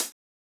ENE Closed Hat.wav